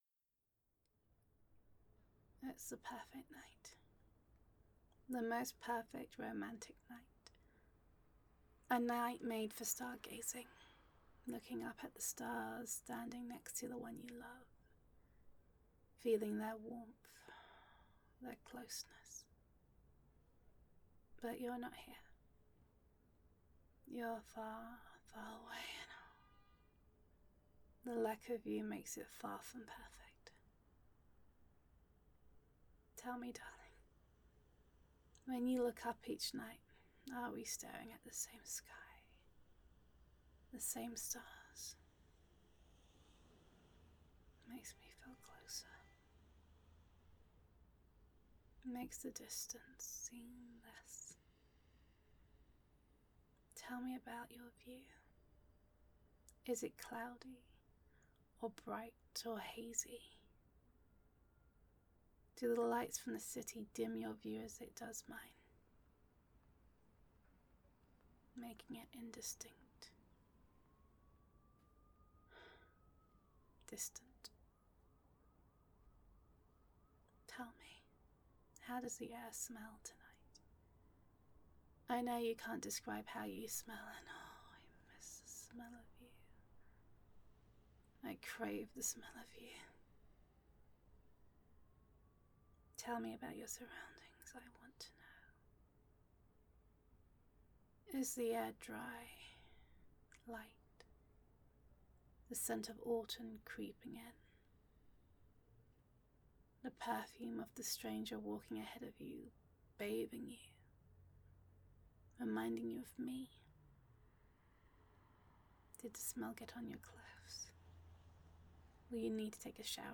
[F4A] For a Moment
[Bittersweet][Longing][Intimate]
[City Sounds]